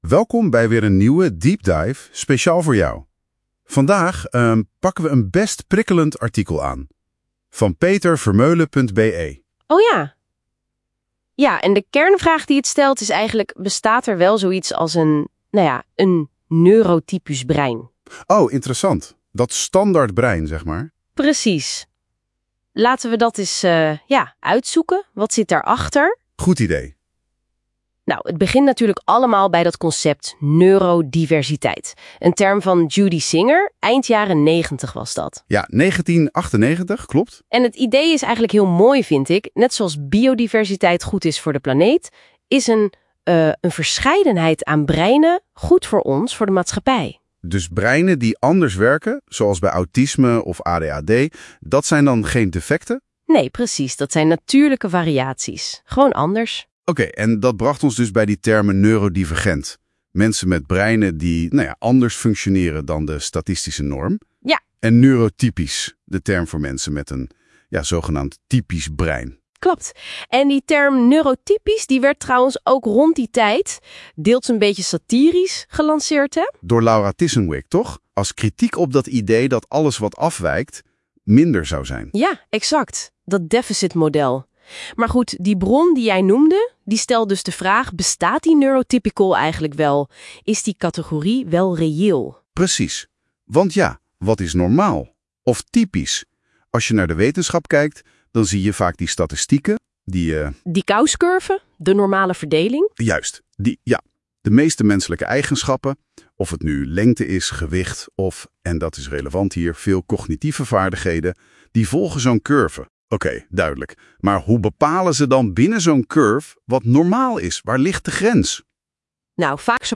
Het oorspronkelijk (Engelstalig) artikel is intussen besproken door Google Gemini.